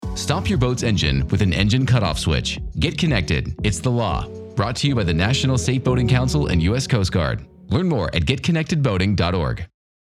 Radio PSA
10-second radio ad encourages boaters to "Get Connected"
get-connected-radio-10.mp3